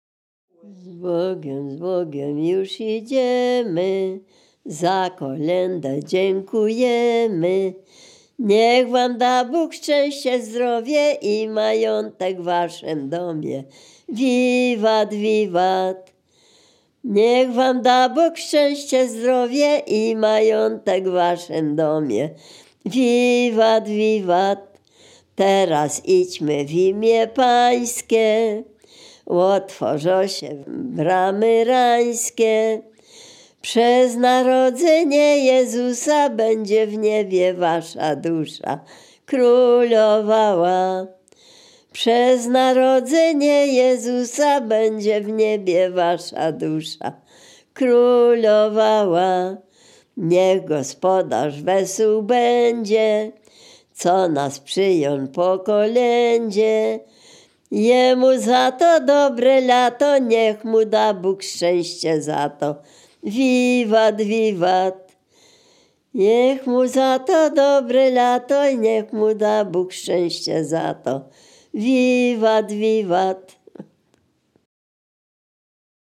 Dolny Śląsk, powiat bolesławiecki, gmina Nowogrodziec, wieś Zebrzydowa
Kolęda
gody kolędowanie kolędy kolędy życzące zima